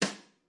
VSCO 1打击乐库 鼓 " 小鼓（小鼓3 rimshot ff 1
Tag: 边敲击 打击乐器 小鼓 VSCO-2 单票据 多重采样